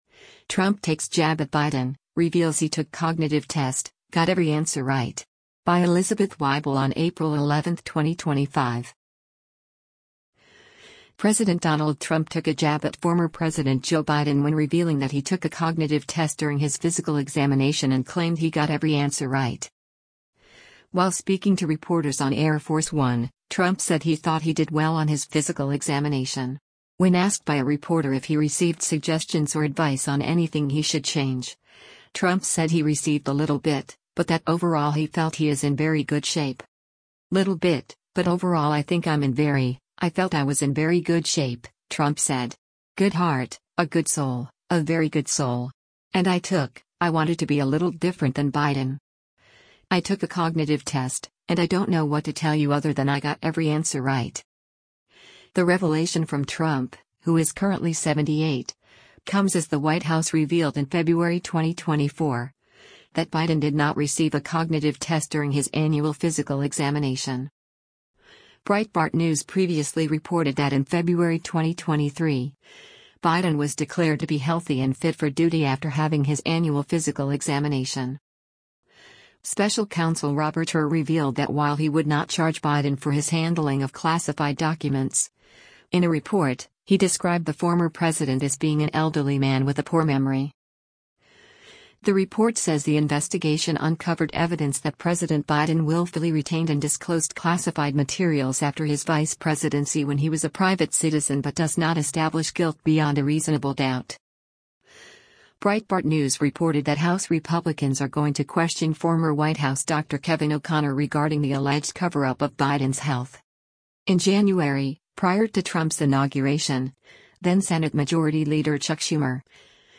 While speaking to reporters on Air Force One, Trump said he thought he “did well” on his physical examination.